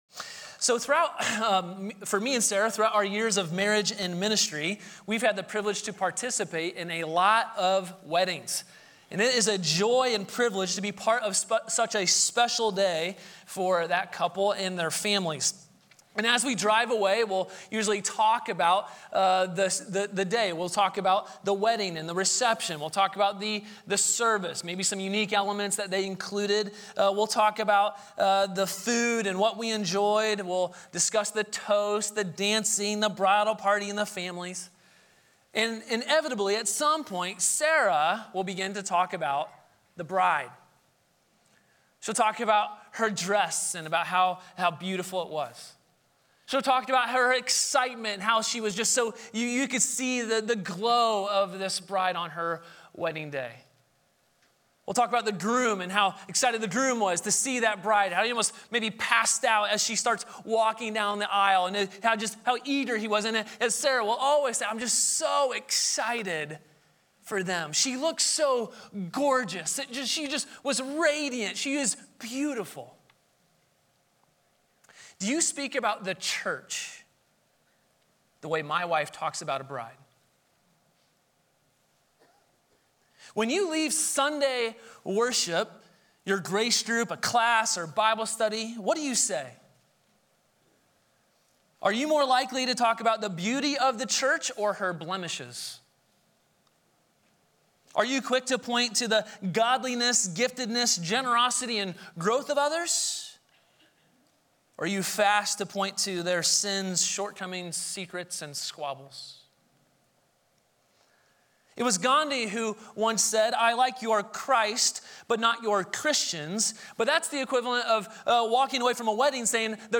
Sermons • Grace Polaris Church